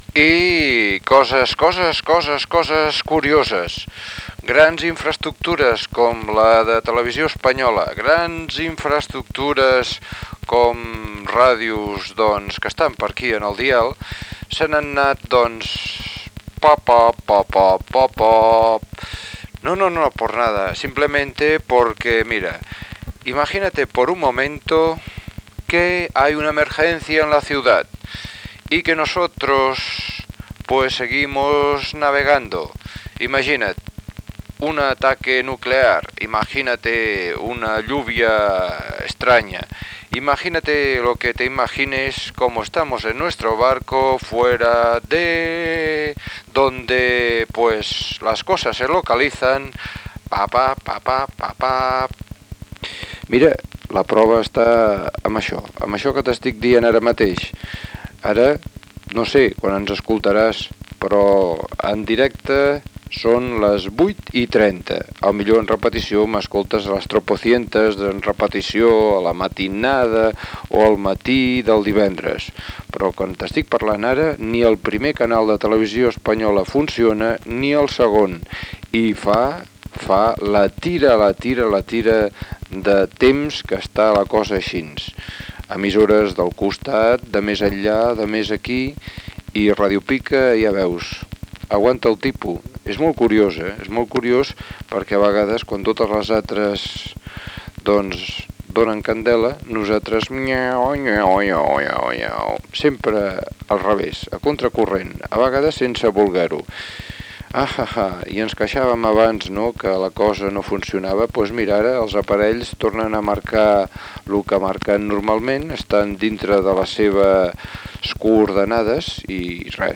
Comentari
Banda FM